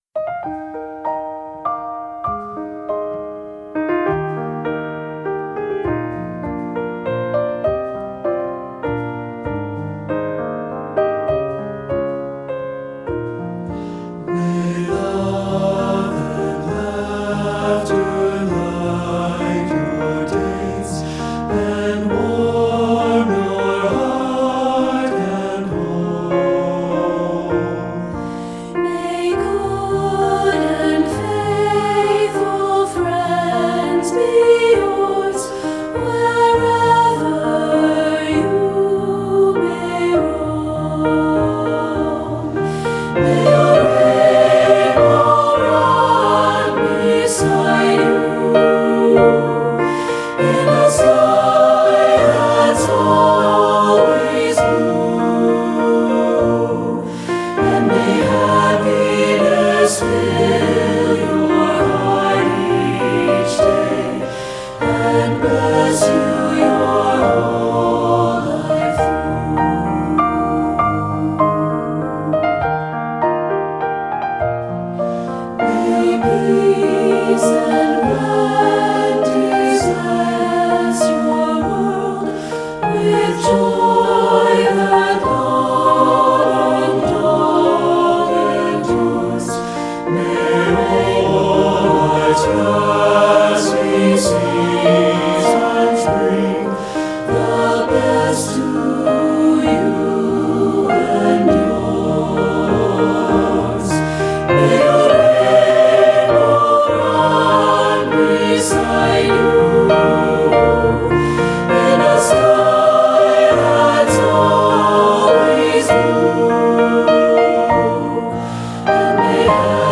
Choral Concert/General Graduation/Inspirational
an expressive parting song
SATB